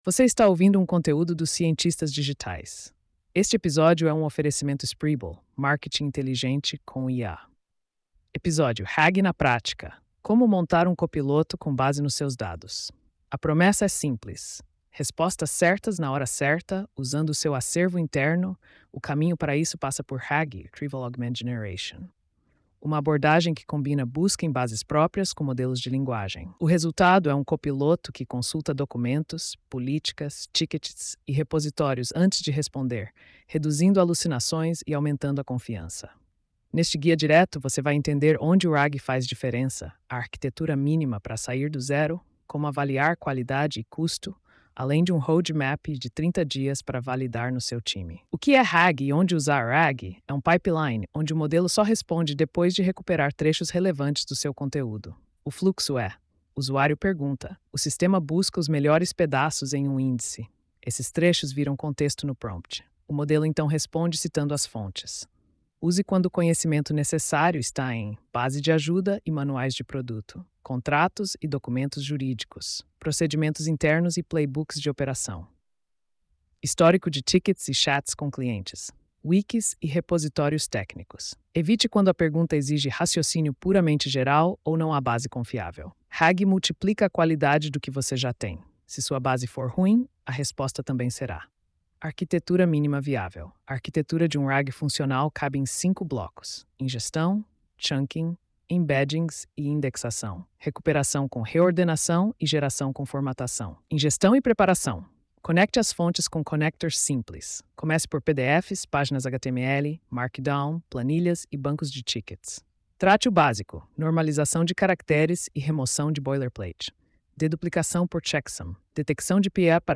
post-4288-tts.mp3